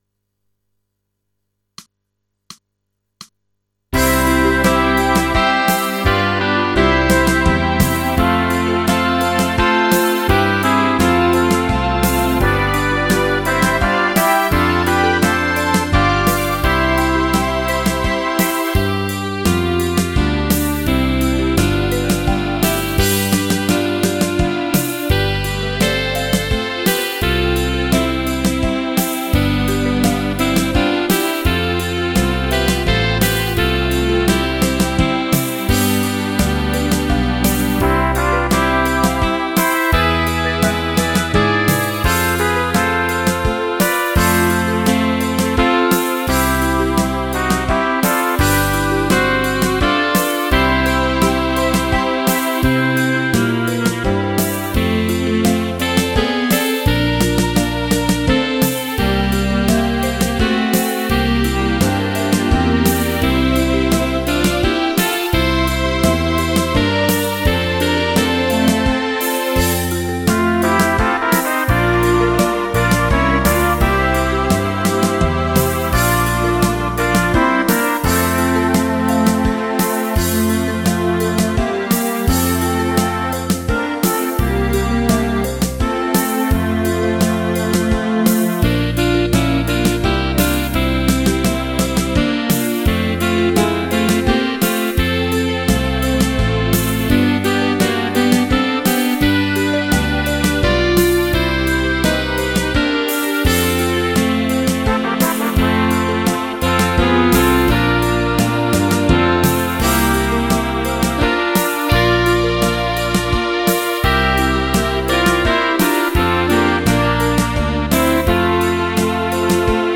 Rubrika: Národní, lidové, dechovka
- waltz